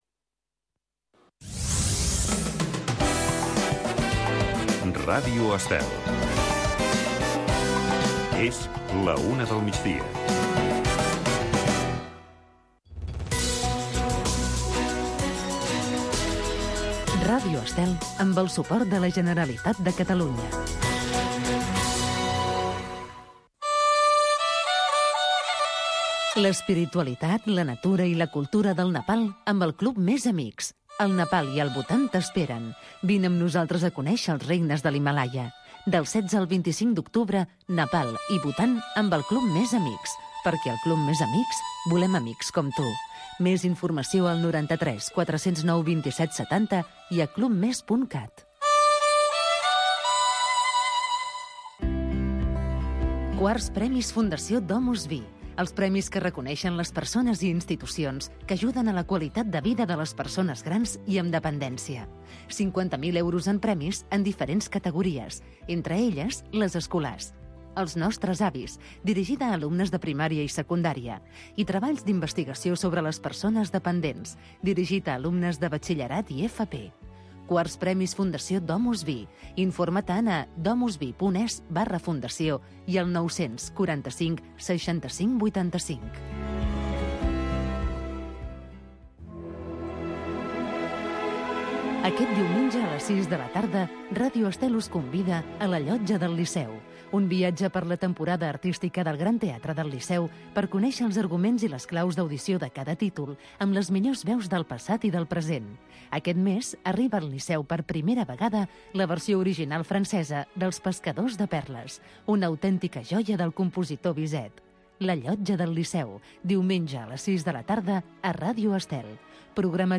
Tradicions, festes, cultura, rutes, combinat amb entrevistes i concurs per guanyar molts premis.